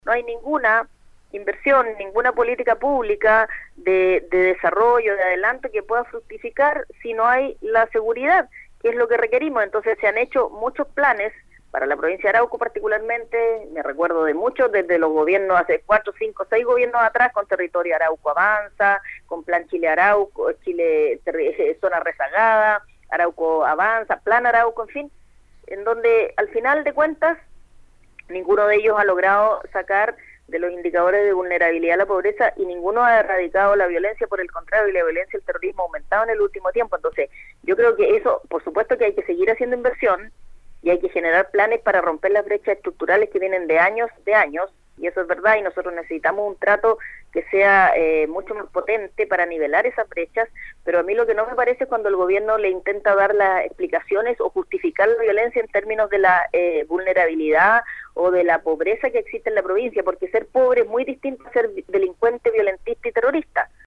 La diputada por el Distrito 21 de la Región del Biobío, Flor Weisse Novoa (UDI), conversó con Radio UdeC sobre la crisis de inseguridad de la Macrozona Sur, dado que Arauco y Biobío – las dos provincias que representa en la Cámara Baja– se encuentran bajo estado de excepción constitucional de emergencia, debido a las situaciones de violencia que continúan afectando a sus habitantes.